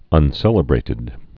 (ŭn-sĕlə-brātĭd)